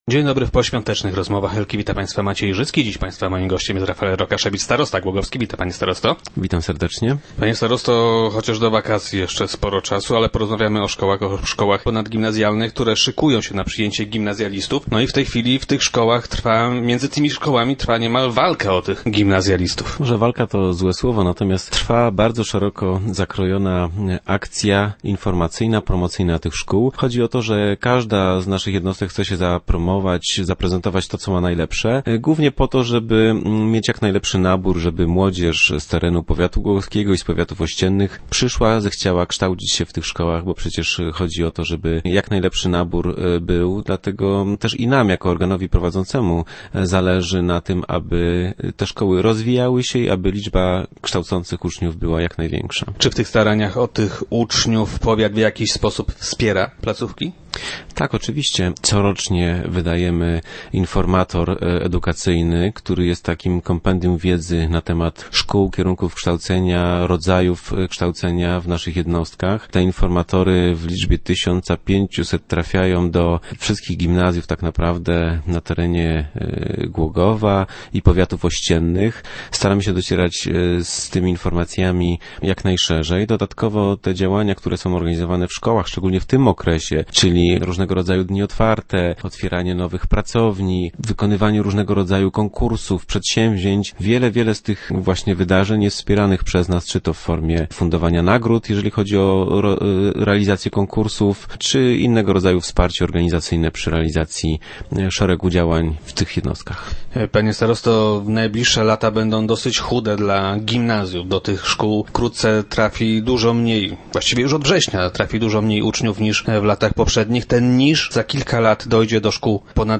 - Już teraz z każdym rokiem coraz mniej uczniów rozpoczyna naukę w naszych szkołach. W związku z tym cały czas monitorujemy sytuację. W przyszłości także będziemy trzymać rękę na pulsie, bo ta sytuacja związana jest z finansami, a za nie odpowiada już powiat - powiedział na radiowej antenie starosta.